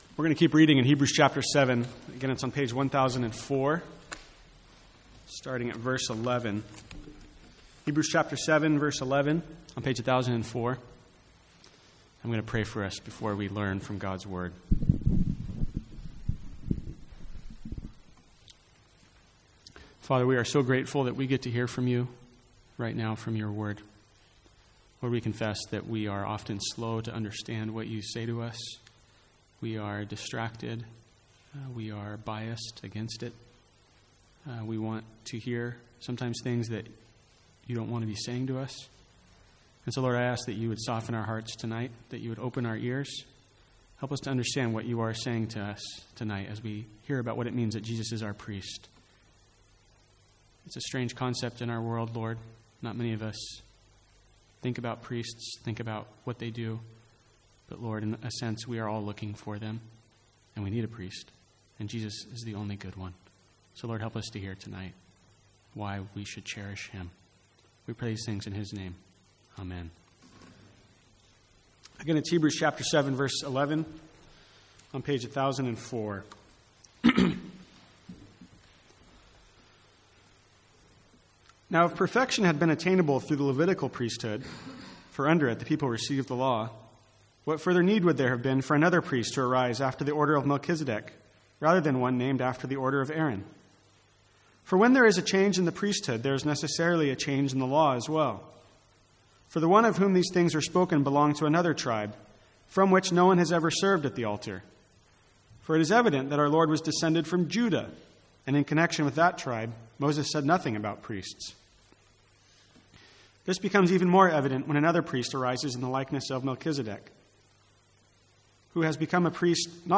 Sermons | St Andrews Free Church
From the Sunday evening series in Hebrews.